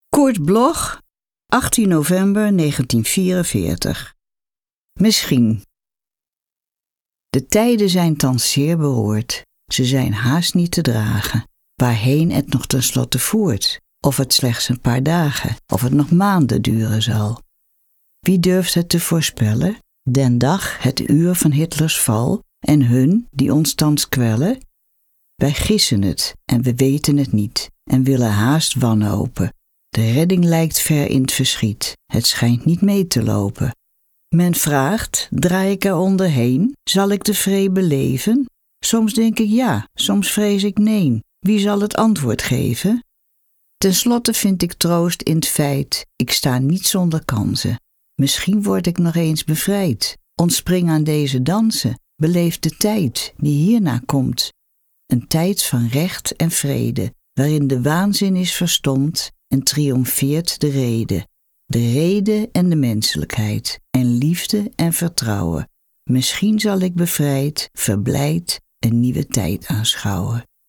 Recording: Studio Levalo, Amsterdam · Editing: Kristen & Schmidt, Wiesbaden